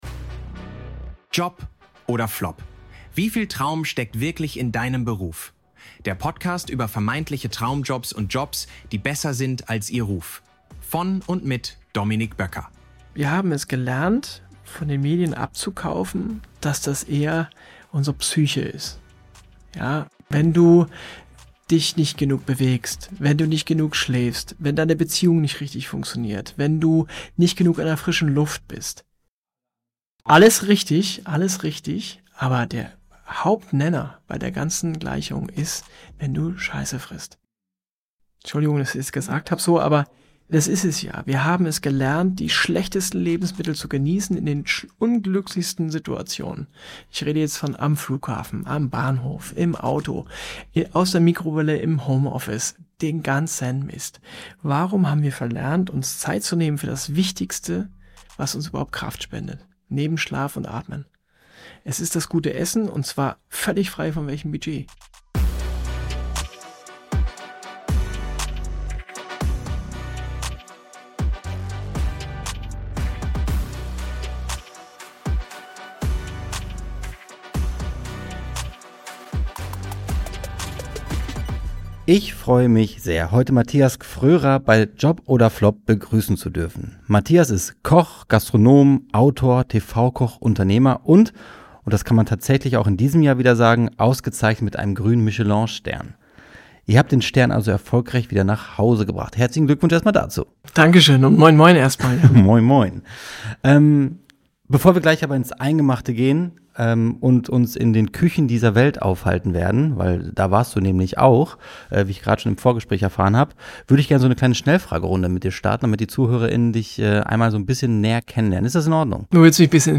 Ein inspirierender, tiefsinniger und überraschender Talk über den Wert guten Essens, die liebe zur Natur und über einen Menschen, der seine Leidenschaft zum Beruf gemacht hat.